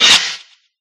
Sword3.ogg